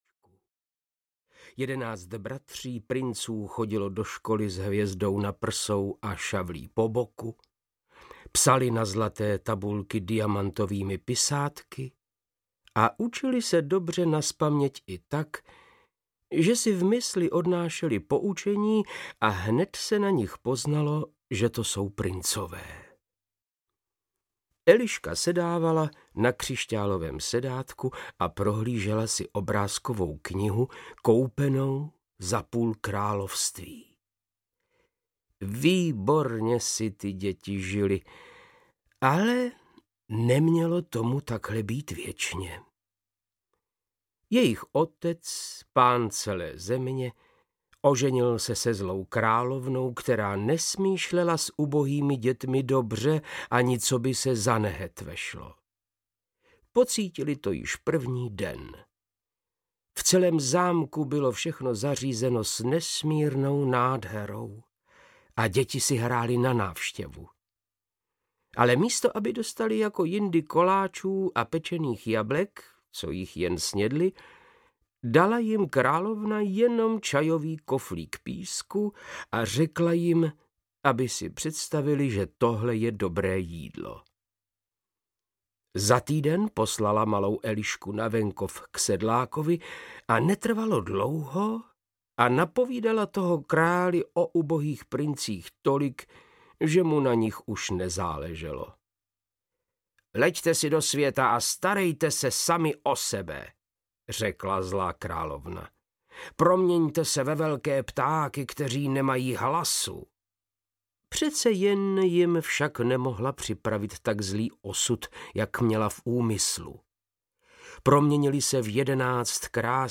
Ukázka z knihy
• InterpretVáclav Knop